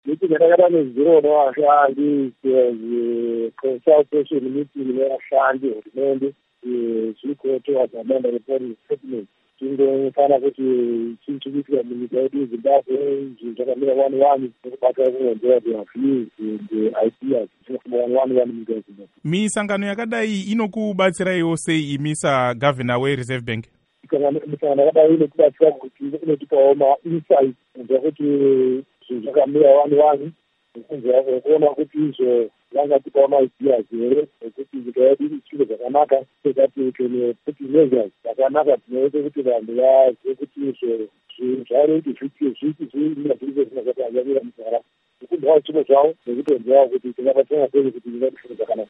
Hurukuro naVaJohn Mangudya